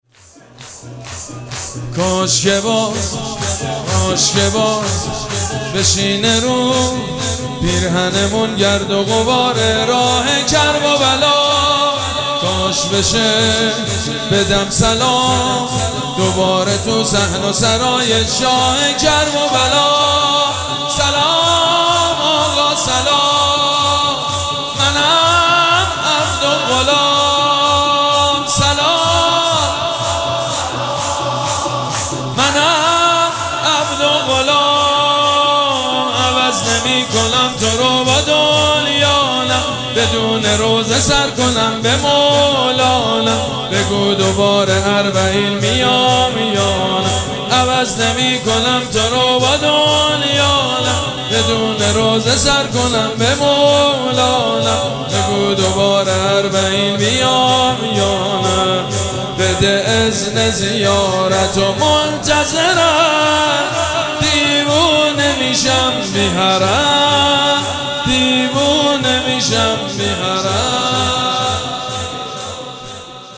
مراسم عزاداری شام شهادت حضرت رقیه سلام الله علیها
شور
مداح
حاج سید مجید بنی فاطمه